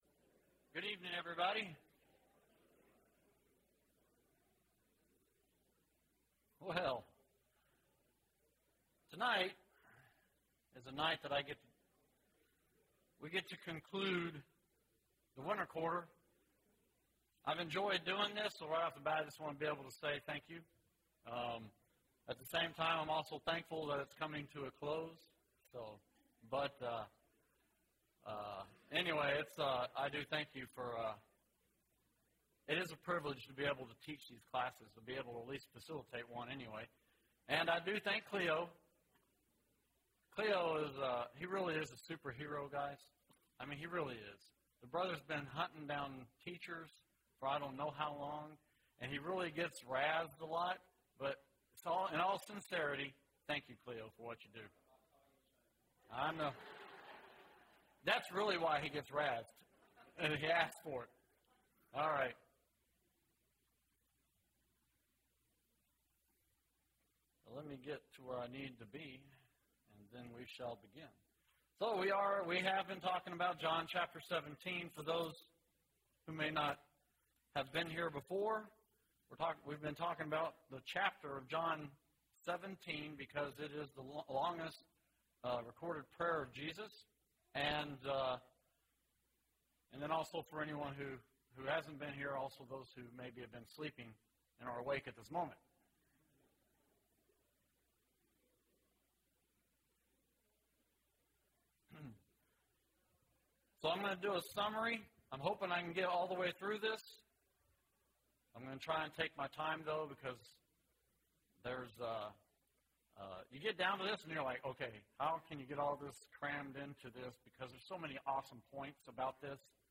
Wednesday PM Bible Class